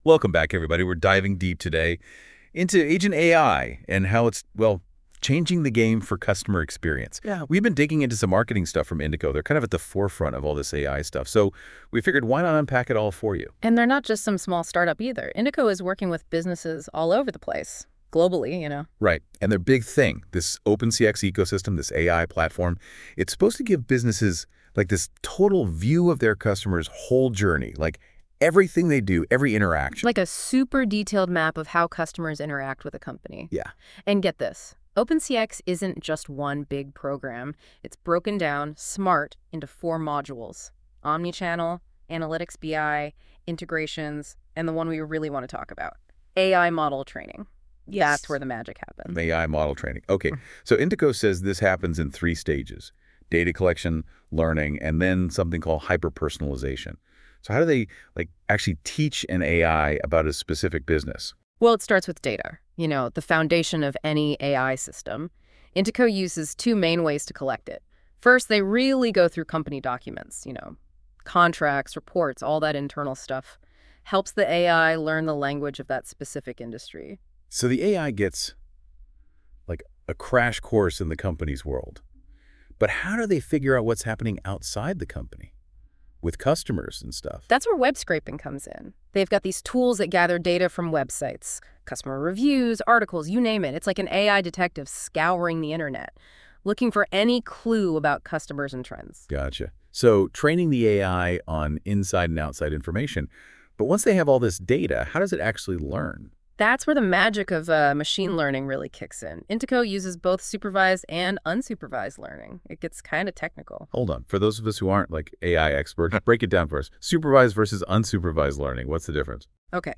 In this podcast episode, Speaker 1 and Speaker 2 discuss the transformative impact of agent AI on customer experience, focusing on IntiCo's innovative AI platform. They explore how IntiCo's AI enhances interactions across industries like finance, healthcare, hospitality, and retail by providing seamless, personalized experiences.